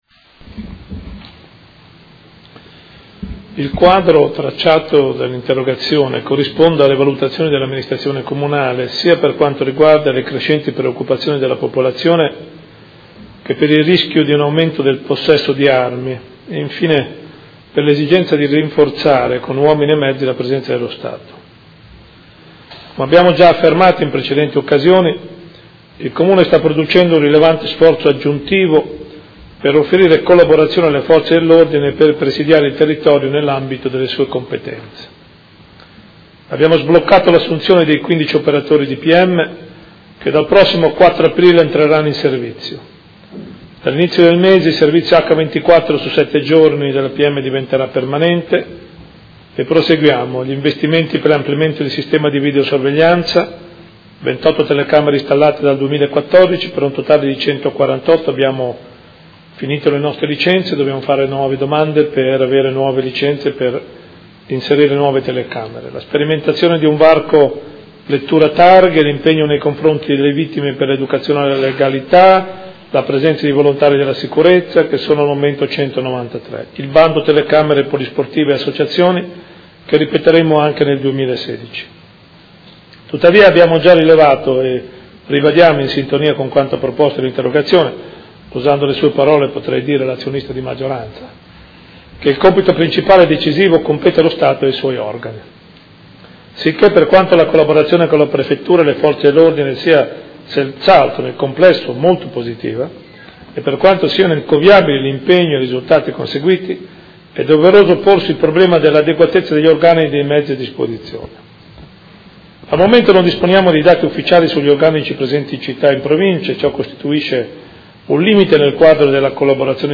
Seduta del 31/03/2016.
Risponde il Sindaco